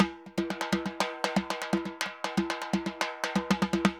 Timba_Merengue 120_2.wav